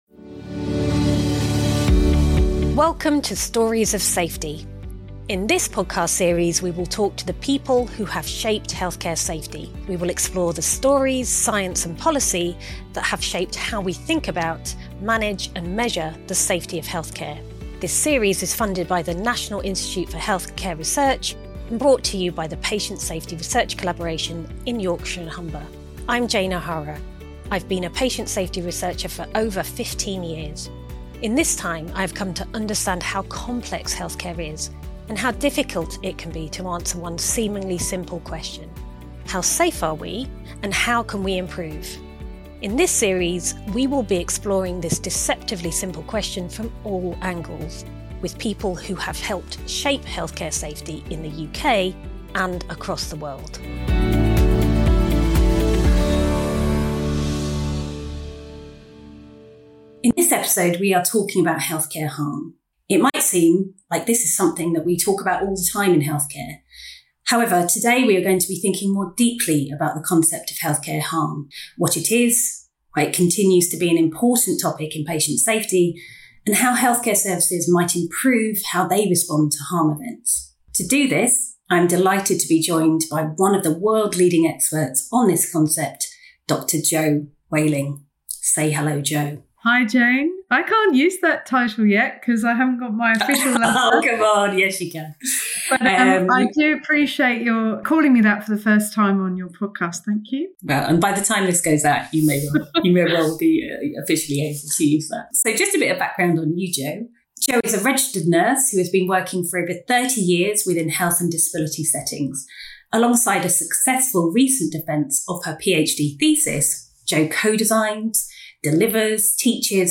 This episode invites listeners to reflect on how we acknowledge and address harm in a way that supports those affected and drives genuine improvement across healthcare systems. A powerful conversation about one of the most human aspects of safety in care.